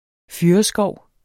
Udtale [ ˈfyɐ̯ʌ- ]